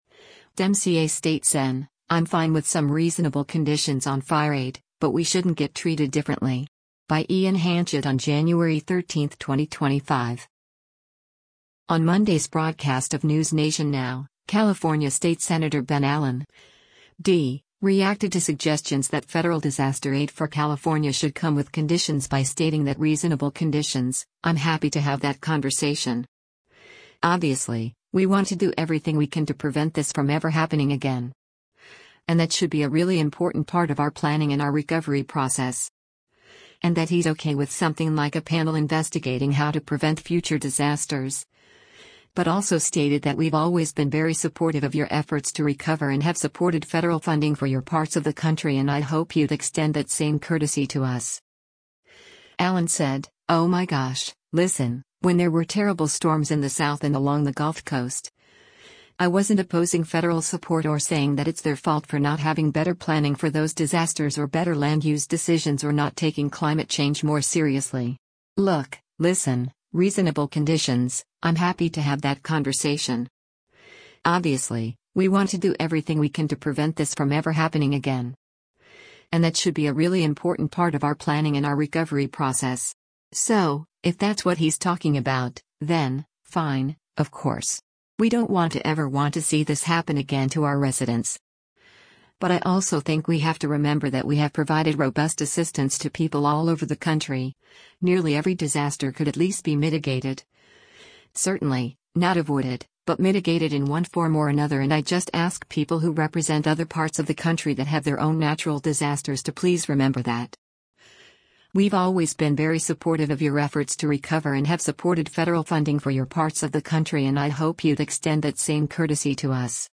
On Monday’s broadcast of “NewsNation Now,” California State Sen. Ben Allen (D) reacted to suggestions that federal disaster aid for California should come with conditions by stating that “reasonable conditions, I’m happy to have that conversation.